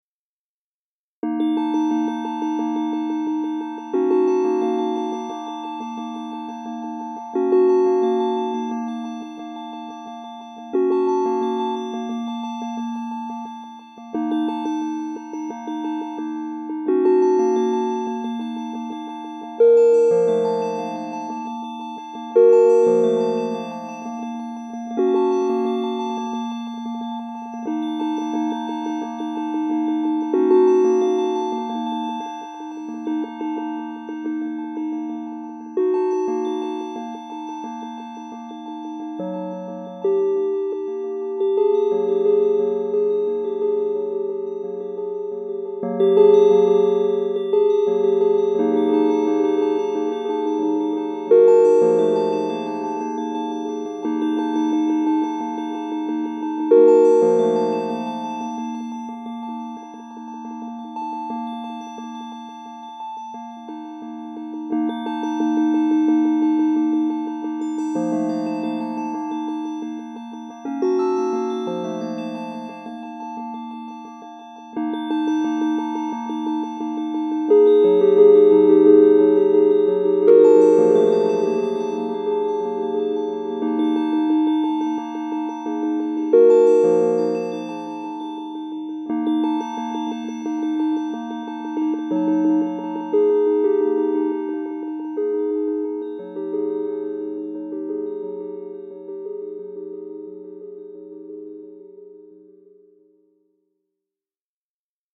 Solo Improvisations